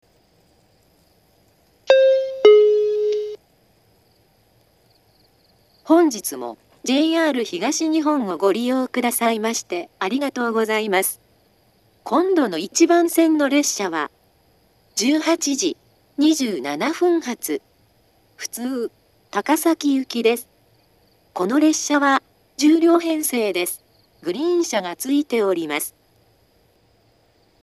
２０１２年頃には放送装置が更新され、自動放送鳴動中にノイズが被るようになっています。
番線到着予告放送